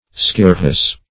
Skirrhus \Skir"rhus\, n. (Med.)